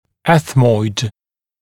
[‘eθmɔɪd][‘эсмойд]решетчатый, решетчатая кость